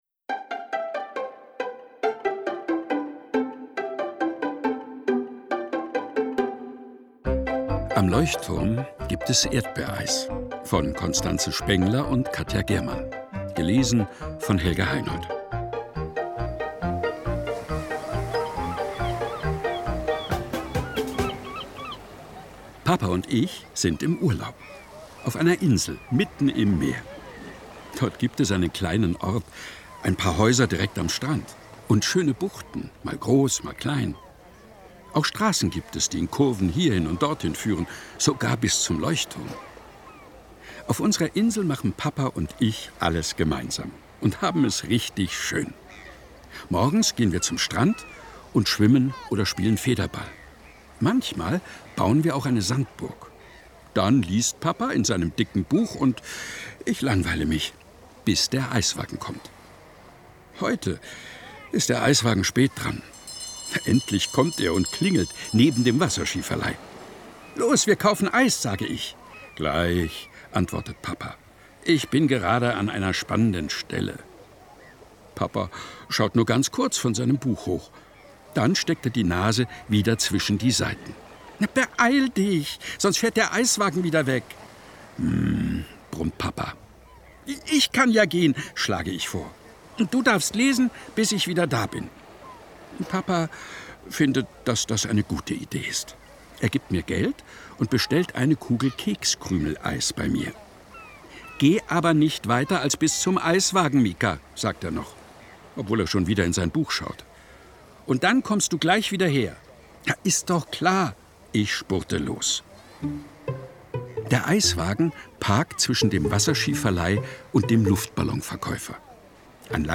Ungekürzte szenische Lesungen mit Musik